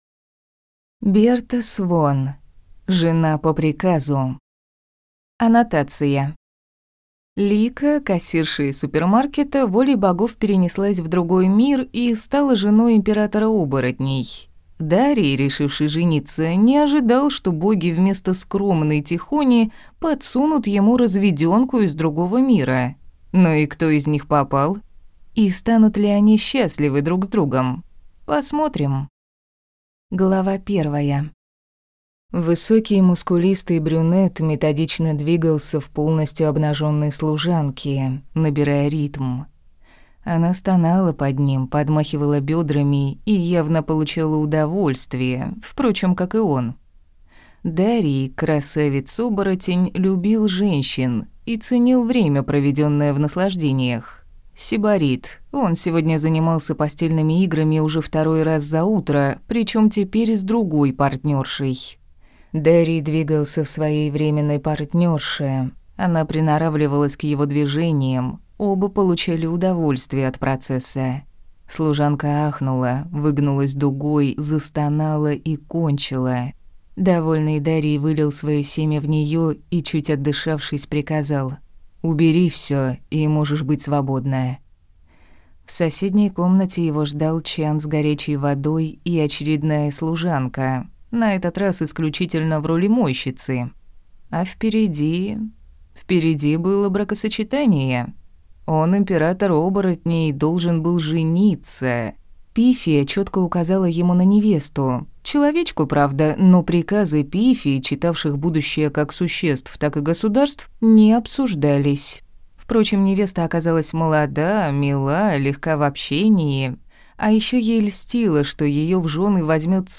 Аудиокнига Жена по приказу | Библиотека аудиокниг
Прослушать и бесплатно скачать фрагмент аудиокниги